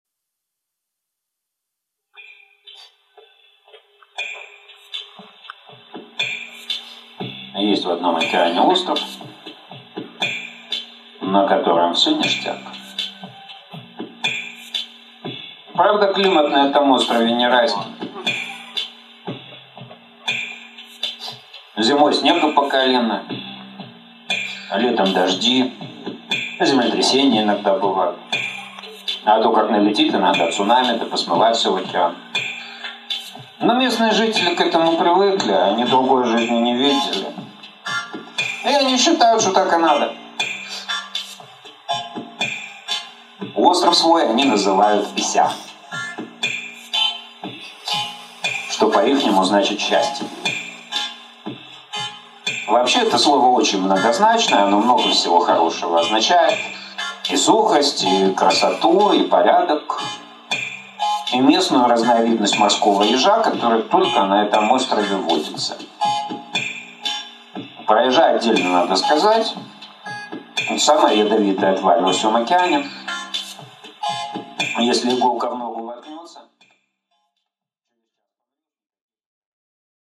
Aудиокнига Про первый снег Автор Дмитрий Гайдук.